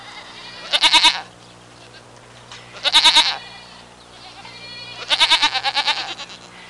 Goat Sound Effect
goat-1.mp3